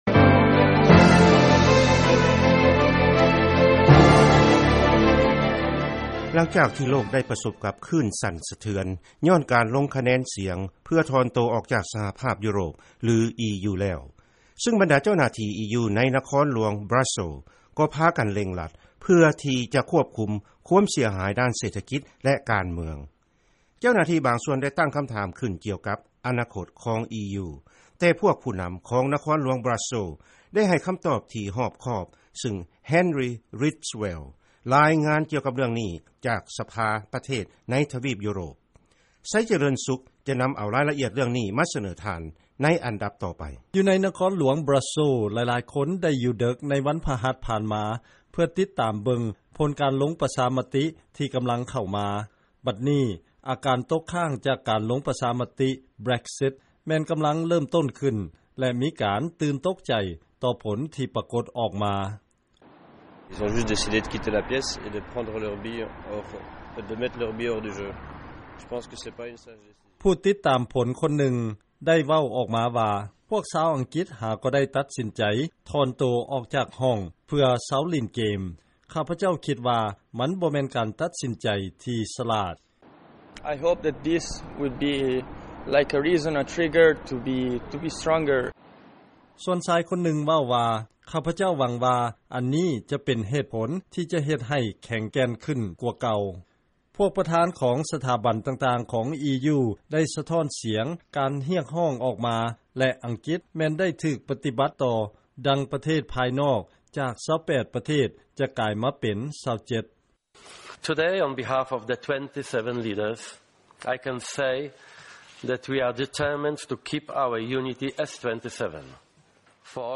ເຊີນຟັງ ລາຍງານ ບຣັສເຊີລສ໌ ຮຽກຮ້ອງ ໃຫ້ເຈລະຈາ ໃນທັນທີ ຫຼັງຈາກ ‘Brexit’ ເພື່ອຈຳກັດ ຜົນກະທົບ